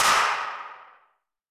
popper.ogg